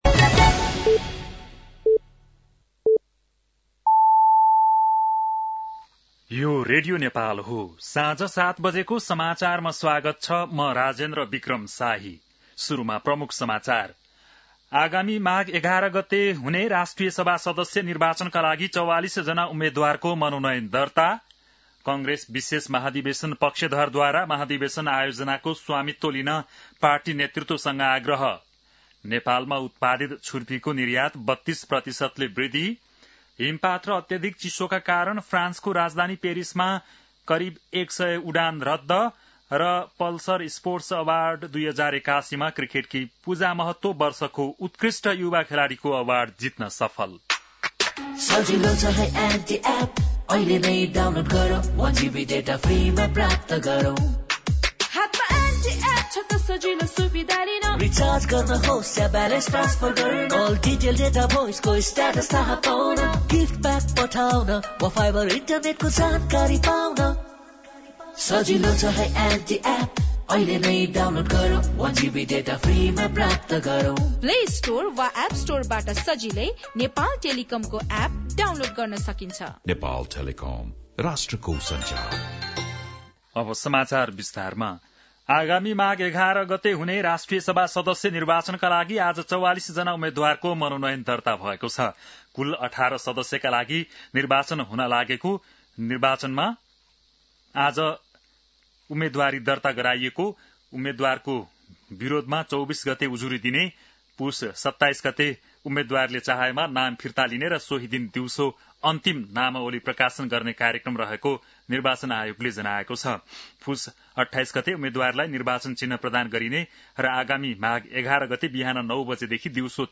बेलुकी ७ बजेको नेपाली समाचार : २३ पुष , २०८२